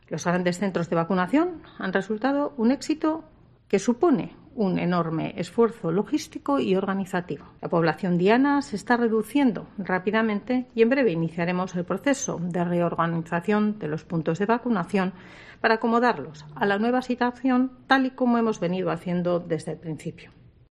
Gotzone Sagardui, consejera de Salud